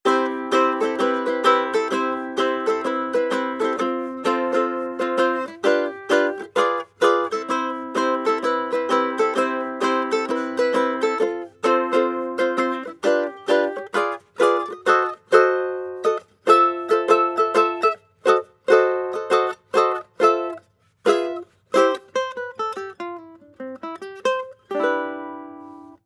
• guitarrico strumming sequence.wav
guitarrico_strumming_sequence_b9h.wav